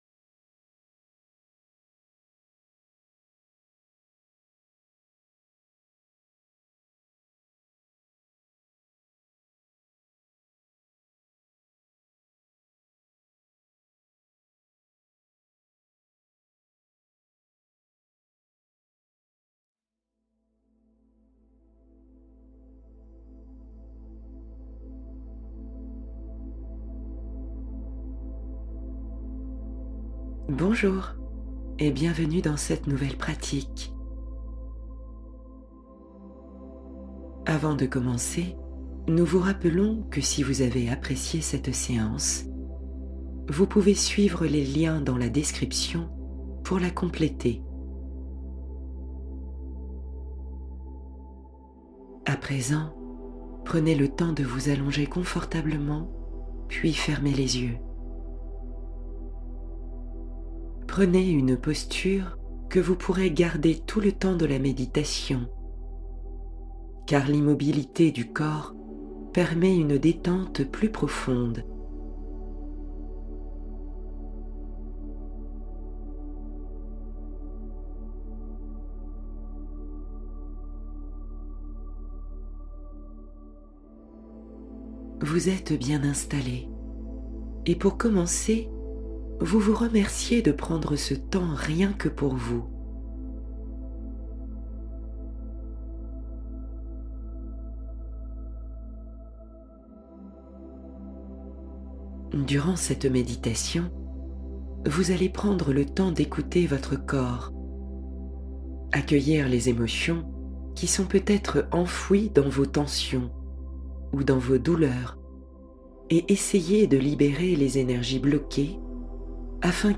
Renouveau cellulaire total — Une plongée guidée au cœur de la détente